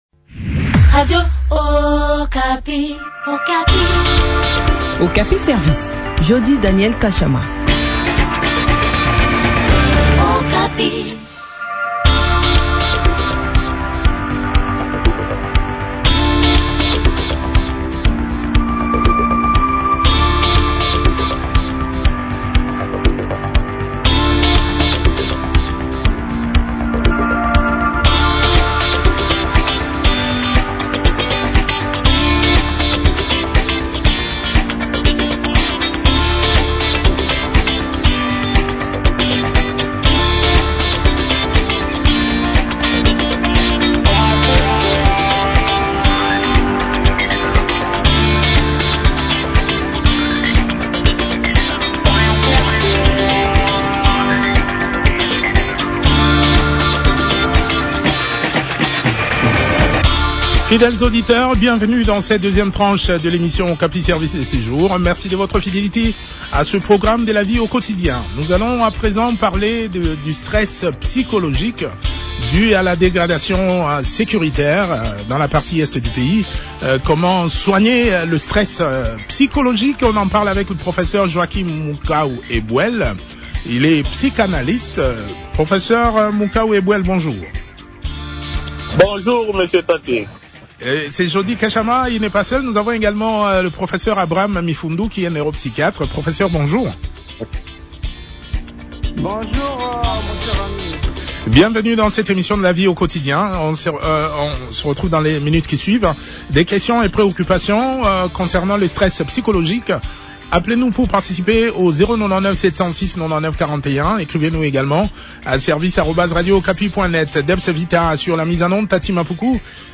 neuropsychiatre a également pris part à cet entretien.